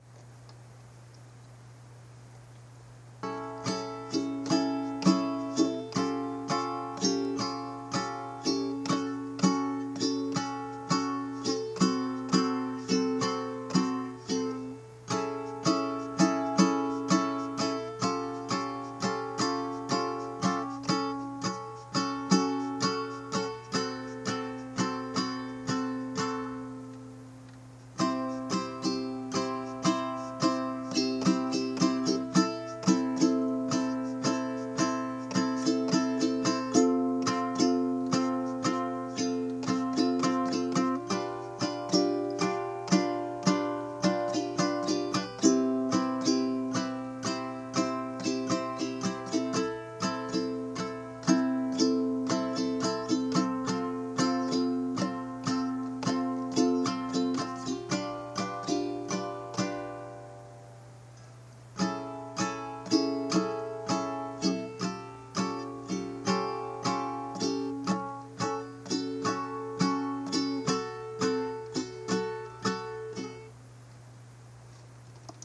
Messing around on my new tenor ukulele